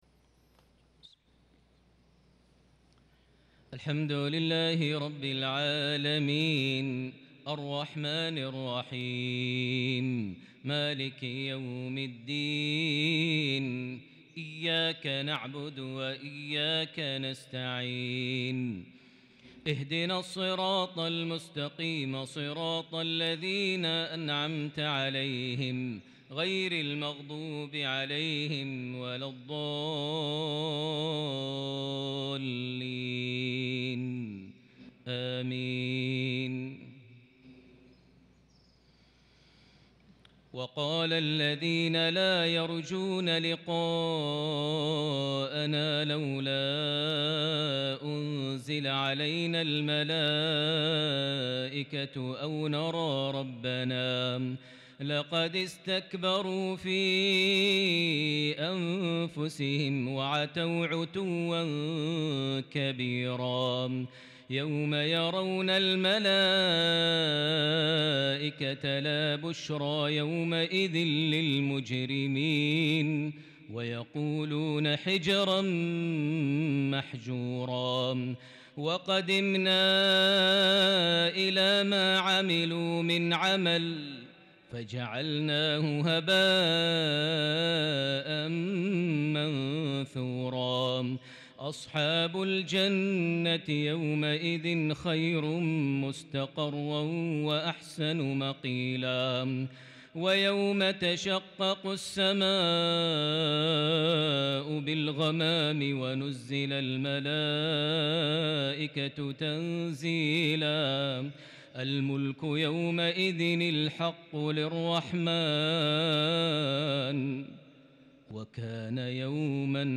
mghrip 6-9- 2021 prayer from Surah Al-Furqan 21-33 > 1443 H > Prayers - Maher Almuaiqly Recitations